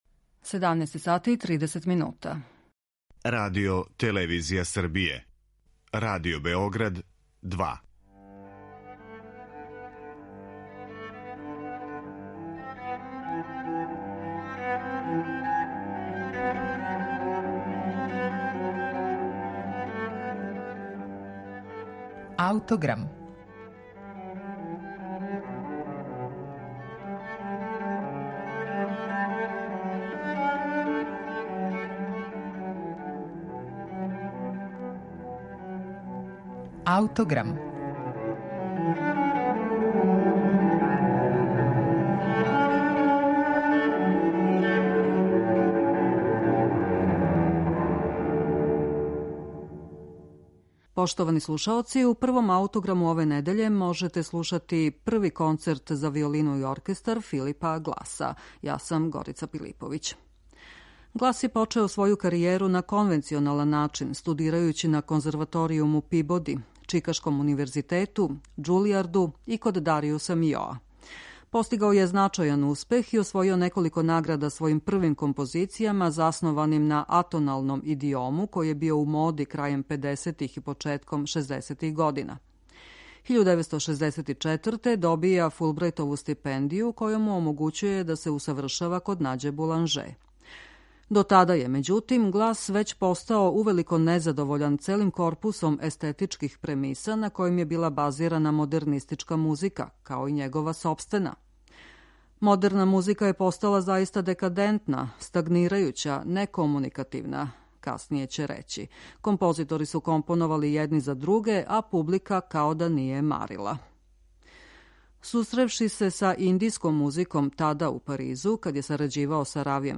Он је блиско сарађивао са Гласом у процесу обликовања овог широко конципираног дела пуног ритма, хармонске енергије и разноврсних инструменталних боја. Глас је у почетку планирао пет краћих ставова, али је на крају прихватио традиционалну троставачну шему.